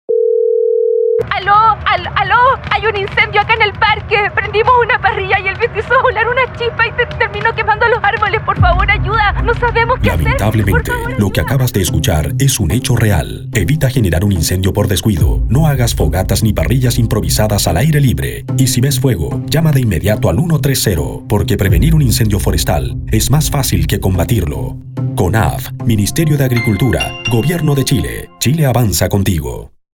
Frases radiales